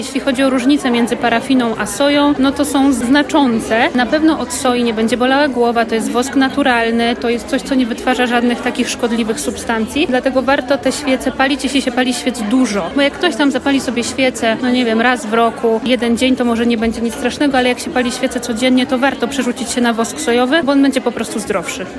W sklepach zazwyczaj są świece parafinowe, naturalne są np. sojowe – wyjaśnia jedna z producentek świec naturalnych „SisCandles”: